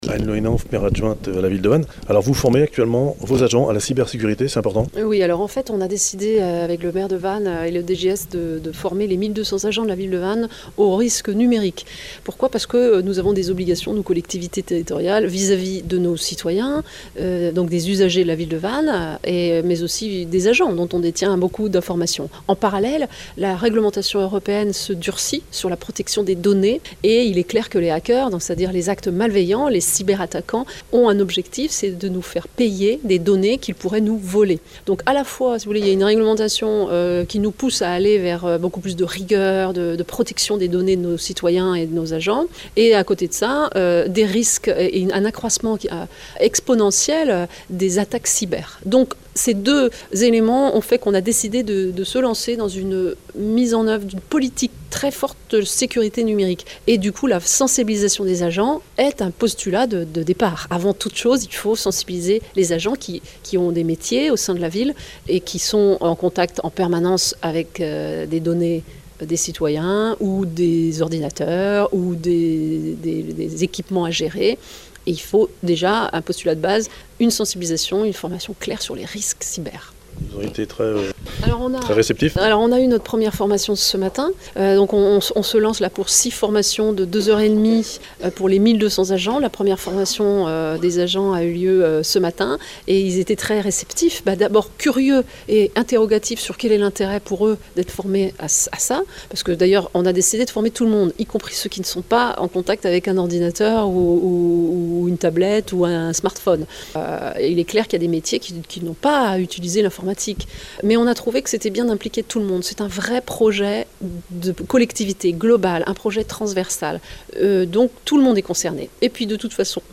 Interview d’Anne Le Henanff – Maire adjointe aux technologies numérique à la Ville de Vannes
Interview